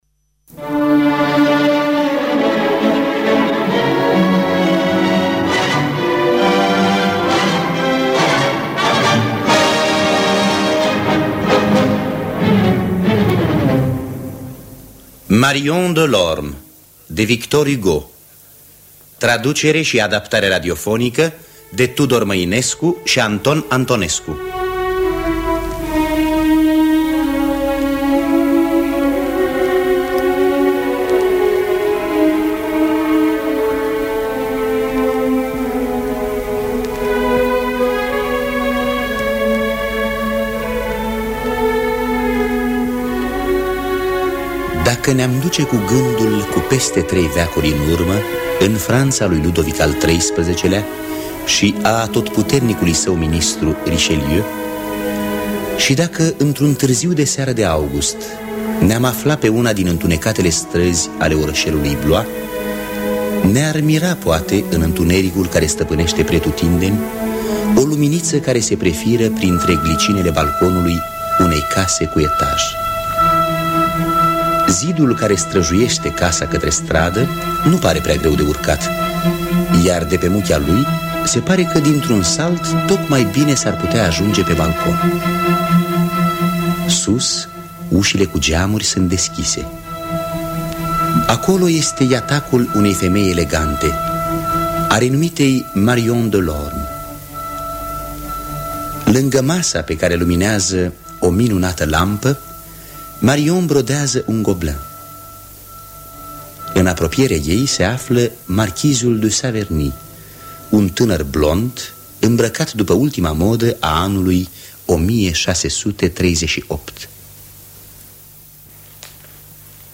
Acompaniament la lăută